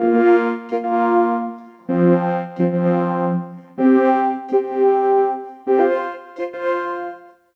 Track 10 - Vocoder 02.wav